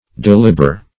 deliber - definition of deliber - synonyms, pronunciation, spelling from Free Dictionary Search Result for " deliber" : The Collaborative International Dictionary of English v.0.48: Deliber \Del"i*ber\, v. t. & i. To deliberate.